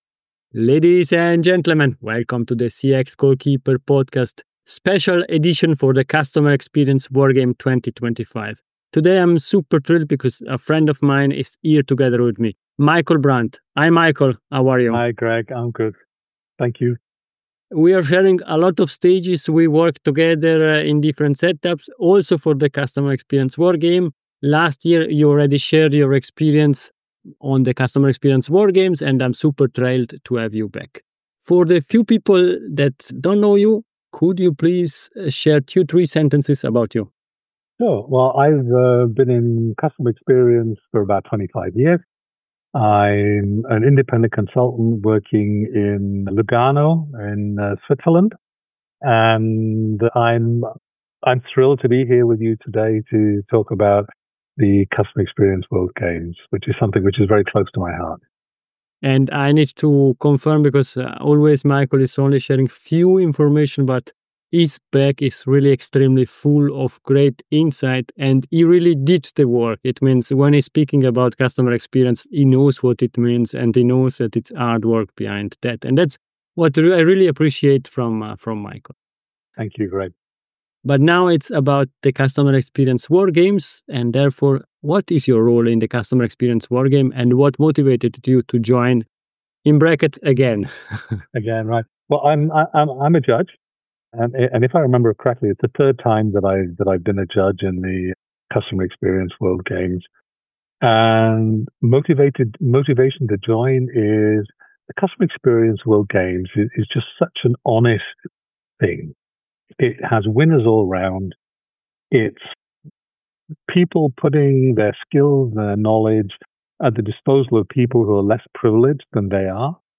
These are brief interviews that share the experiences of outstanding individuals who help charities improve.